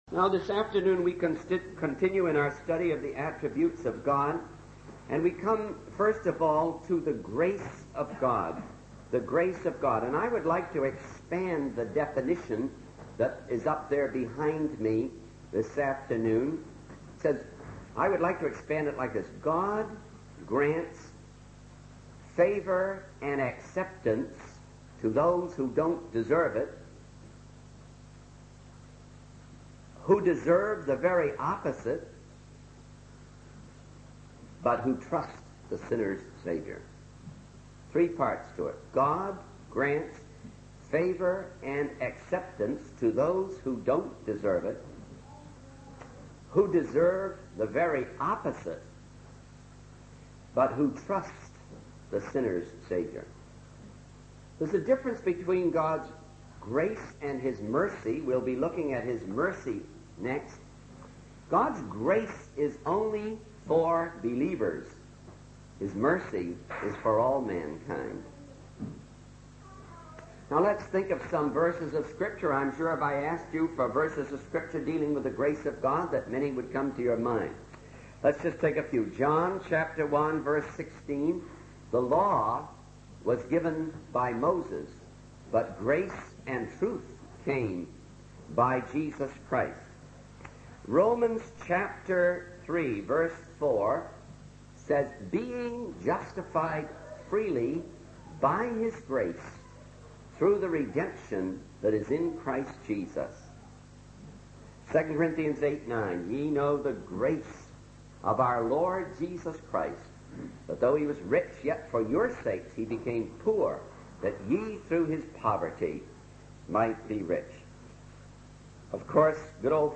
In this sermon, the speaker emphasizes the smallness of the earth in the vast universe, highlighting the insignificance of humanity in comparison. However, despite our sinful state, God showed incredible grace by sending his son Jesus to be born in Bethlehem and suffer on the cross for our salvation.